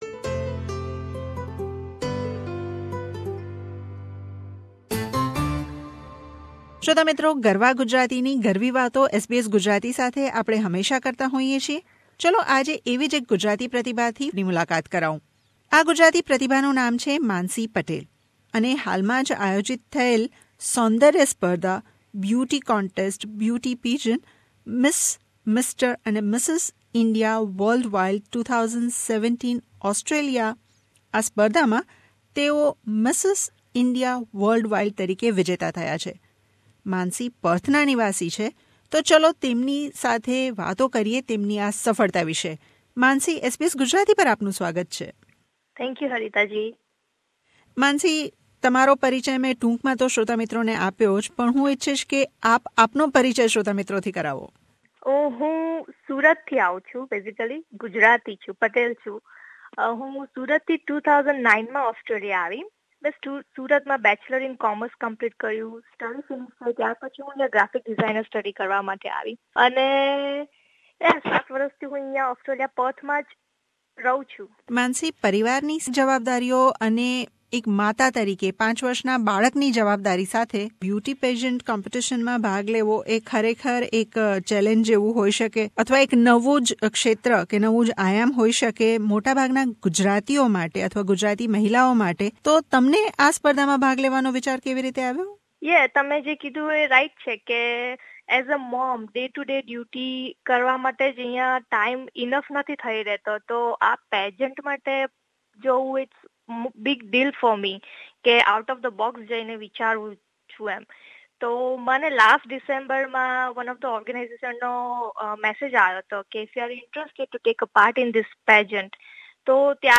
મુલાકાત.